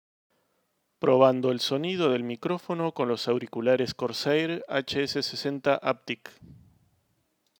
• Unidireccional con cancelación de ruido
Por último el micrófono funciona bastante bien, proporcionando una voz clara y nítida durante las sesiones de juegos.
Prueba de micrófono.